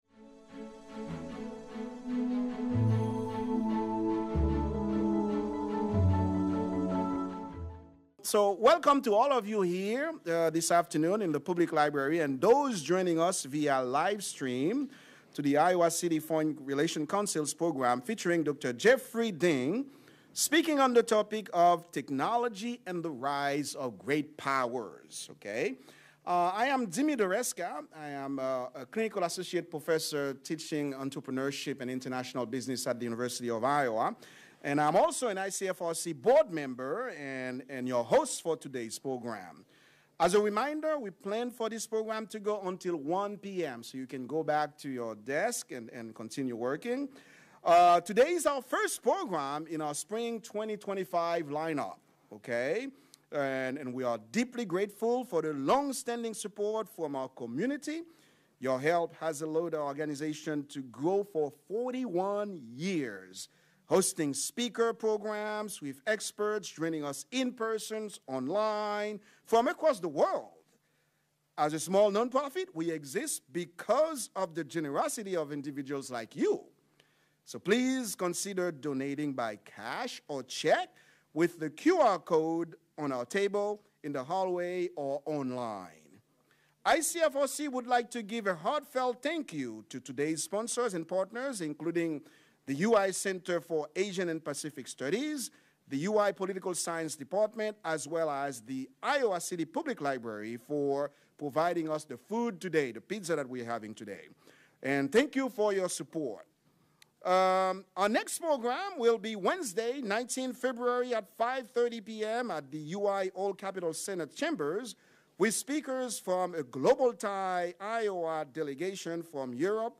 Rather than focusing on which state was the first to introduce major innovations, he investigates why some states were more successful than others at adapting and embracing general-purpose technologies at scale. Presented by the Iowa City Foreign Relations Council in partnership with University of Iowa Departments of Political Science and Center for Asian and Pacific Studies.